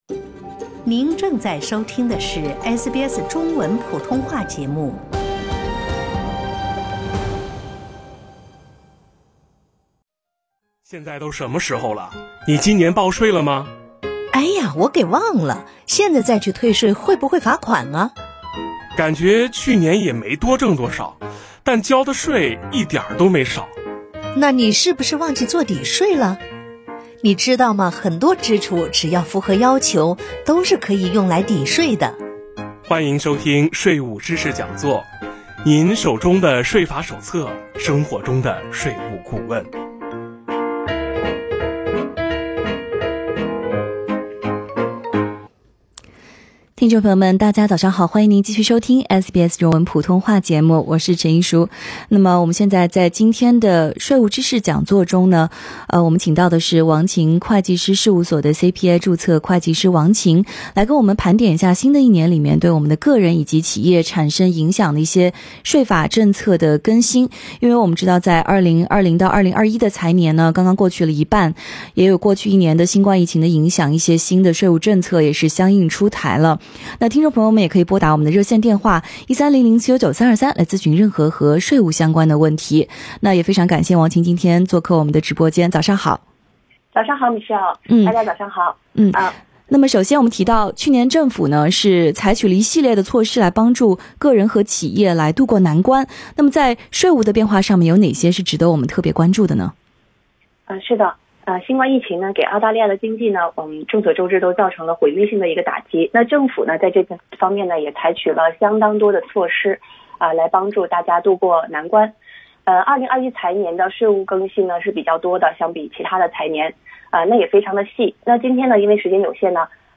【税务知识讲座】：新年里有哪些税务政策的变化？